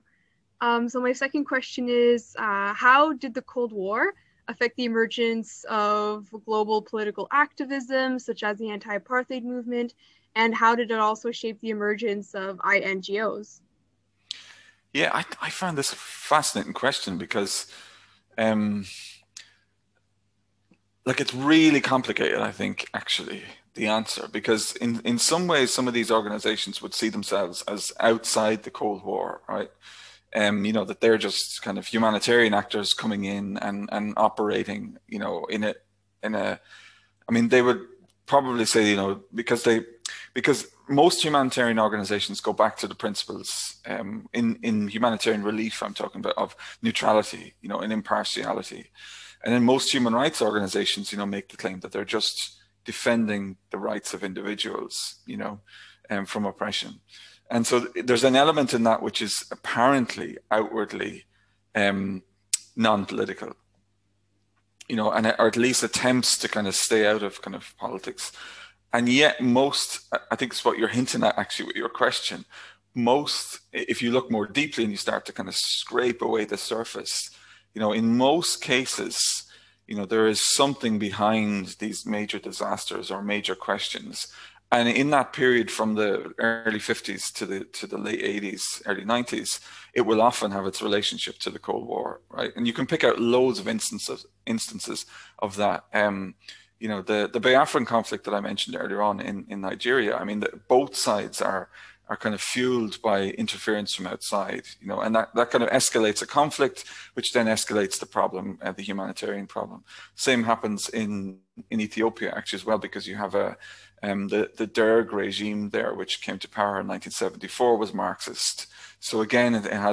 This is the second part of the interview.